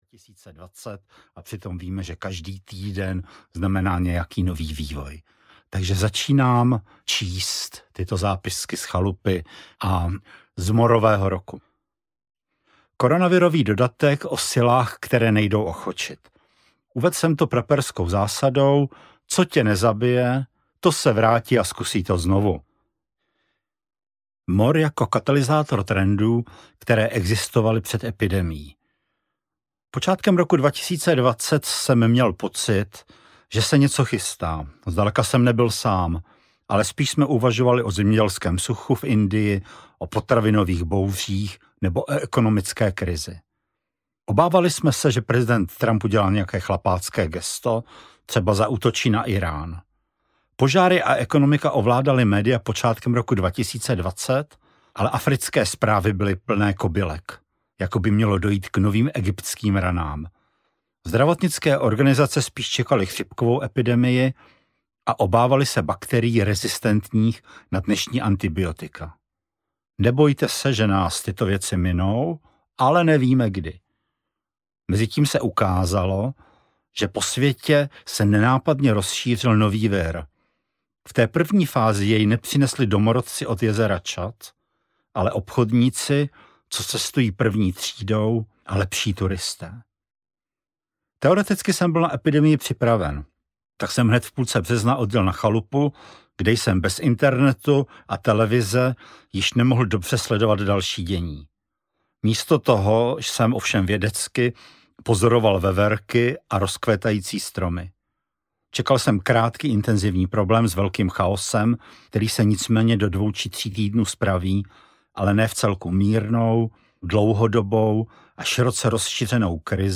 Co se děje se světem? audiokniha
Ukázka z knihy
• InterpretMartin Myšička